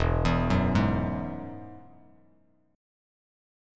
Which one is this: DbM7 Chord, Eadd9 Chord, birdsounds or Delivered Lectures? Eadd9 Chord